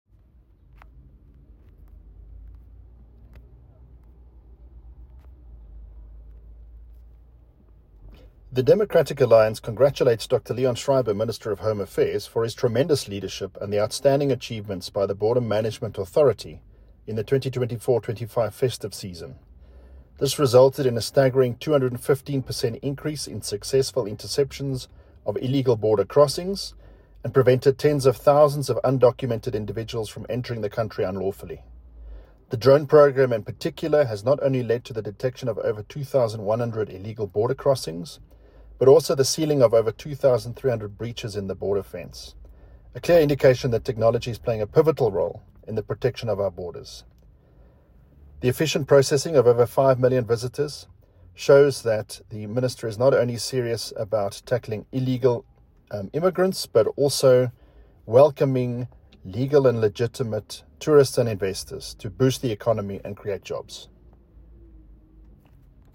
Issued by Adrian Roos MP – DA Spokesperson on Home Affairs
soundbite by Adrian Roos MP.